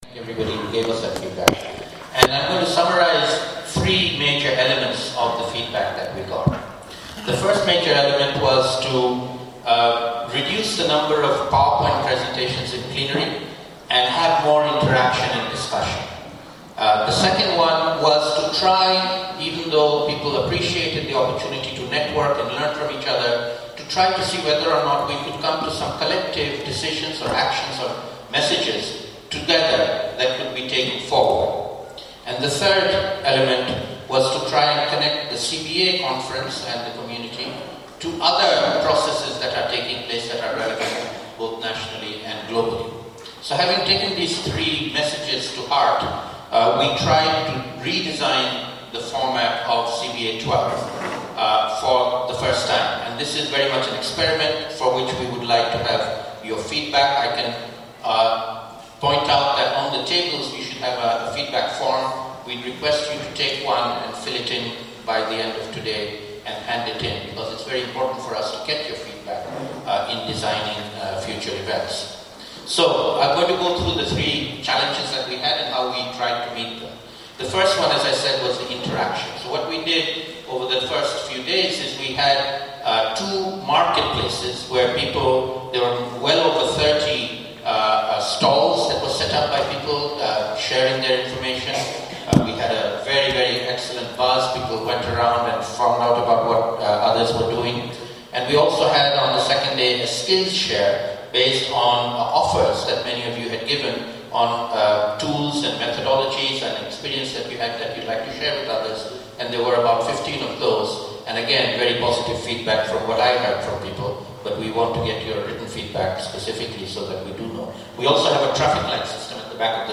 In this audio clip, Saleemul Huq, IIED senior fellow, Climate Change rounds up activities from the first three days of CBA12 and explains proceedings for the NAP Expo which occurred on day four. Huq then sets out how key messages from this community-based event will feed into global climate policy dialogues and processes to ensure voices from the grassroots are heard.